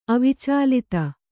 ♪ avicālita